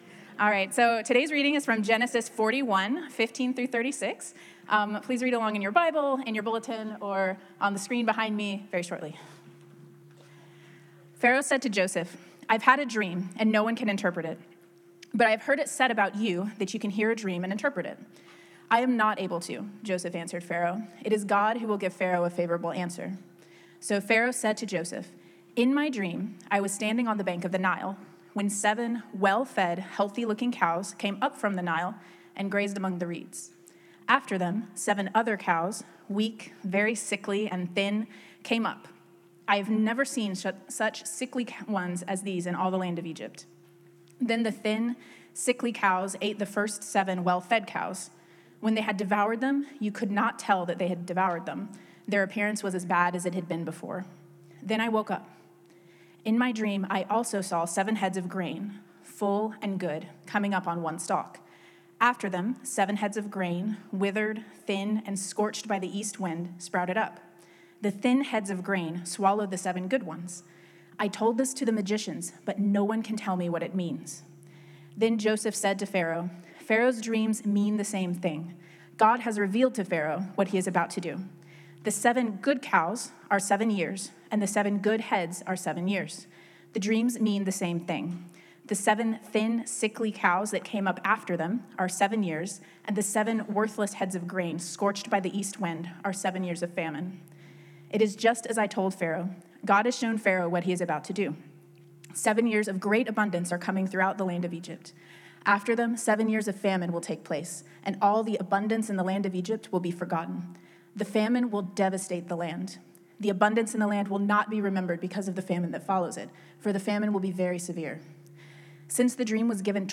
In this sermon on Genesis 40-41, we follow Joseph from prison to palace and discover that the waiting period isn't wasted time—it's a test of character.